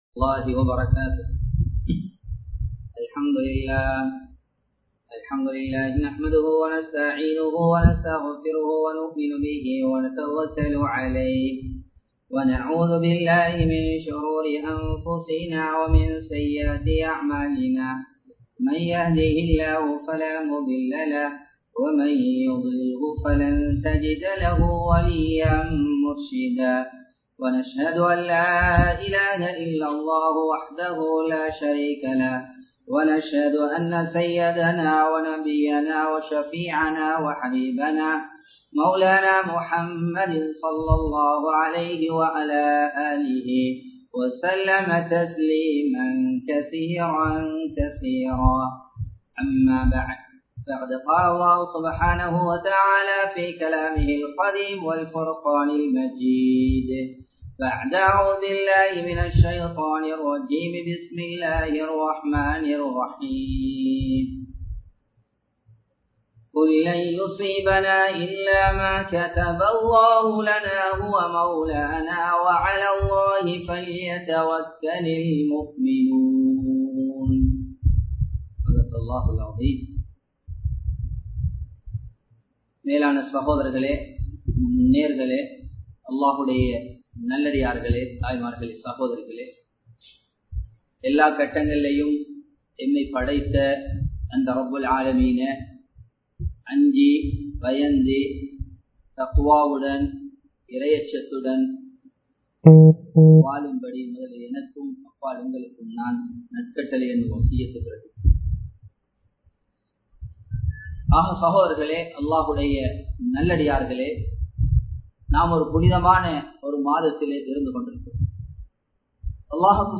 பிர்அவ்ன் கேட்ட தௌபா | Audio Bayans | All Ceylon Muslim Youth Community | Addalaichenai
Live Stream